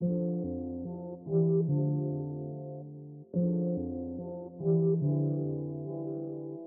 描述：这个循环是用Alchemy制作的。
Tag: 144 bpm Trap Loops Synth Loops 1.12 MB wav Key : F